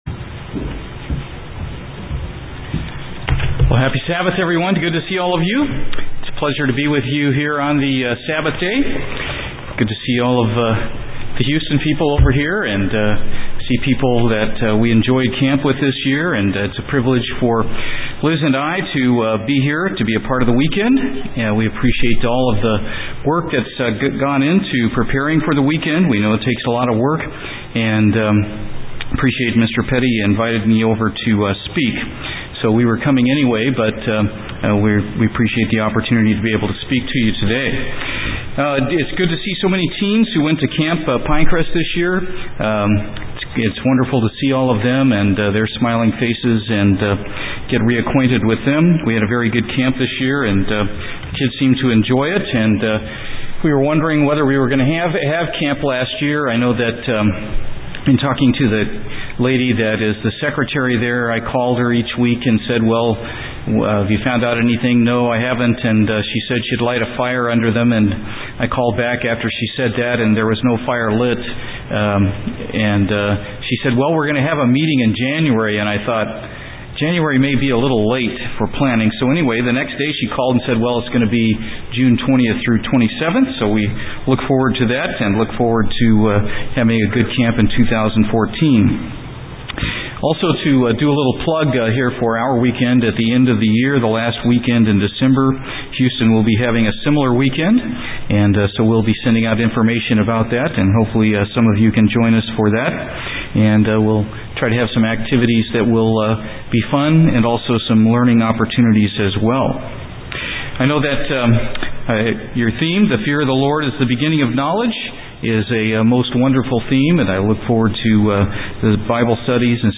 Having the proper fear of God is something we can learn from the biblical characters in the Bible. This sermon covers one specific person in the Old Testament.
Given in San Antonio, TX